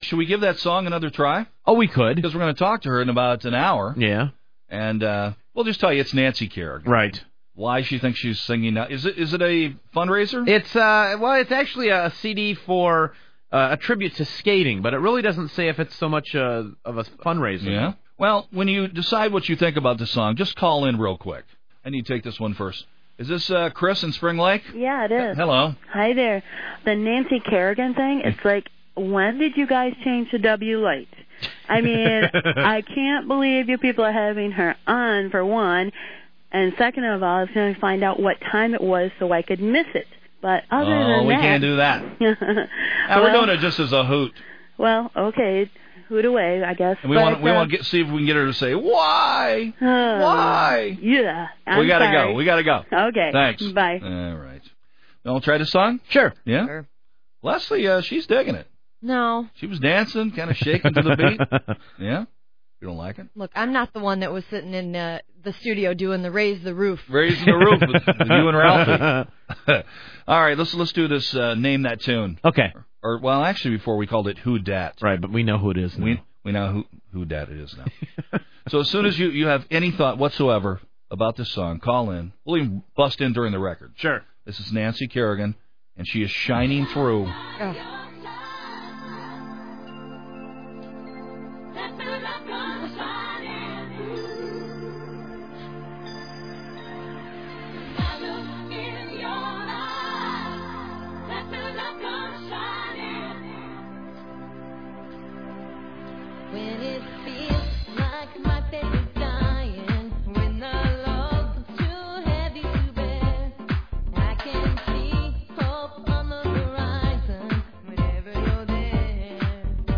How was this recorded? She was running a little late for her phone interview (on November 2nd, 1999), so we played the song one more time.